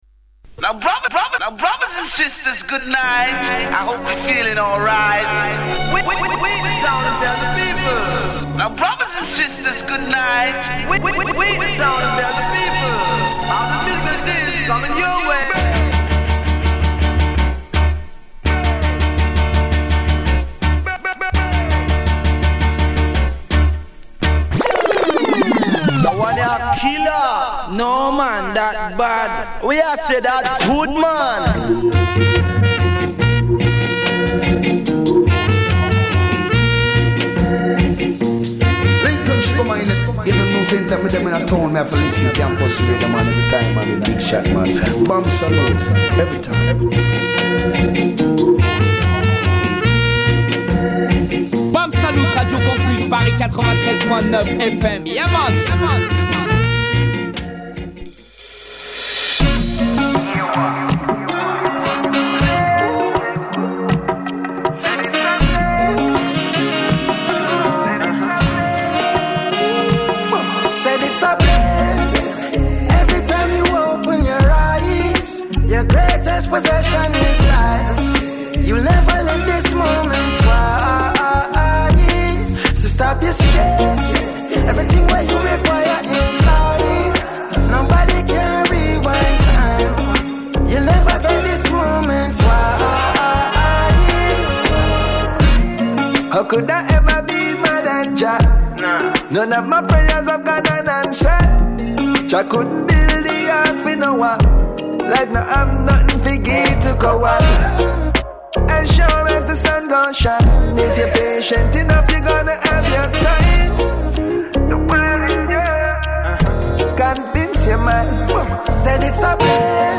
set spécial Rub-a-Dub Dj's